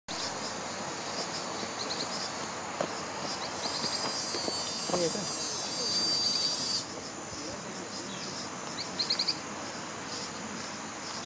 Pijuí Plomizo (Synallaxis spixi)
Nombre en inglés: Spix´s Spinetail
Fase de la vida: Adulto
Localidad o área protegida: Reserva Ecológica Costanera Sur (RECS)
Condición: Silvestre
Certeza: Vocalización Grabada